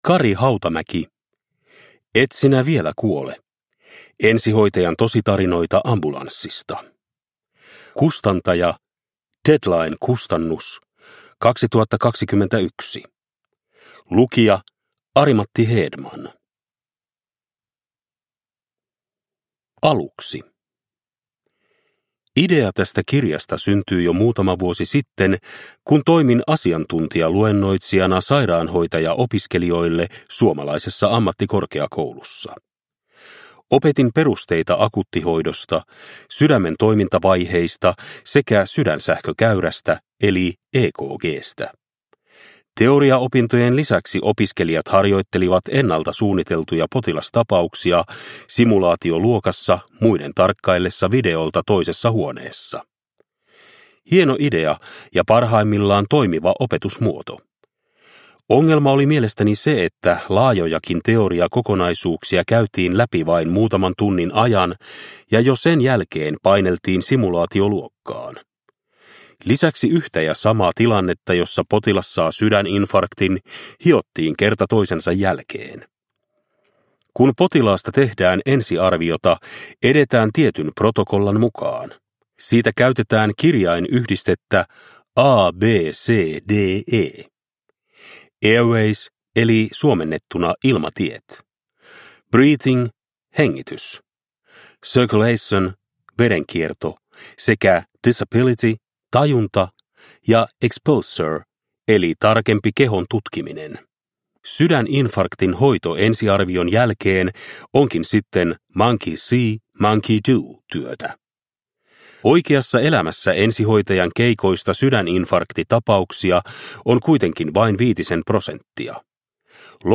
Et sinä (vielä) kuole (ljudbok) av Kari Hautamäki